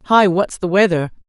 Guided Text to Speech Generator
"voice": "A female speaker with a slightly low-pitched, quite monotone voice delivers her words at a slightly faster-than-average pace in a confined space with very clear audio.",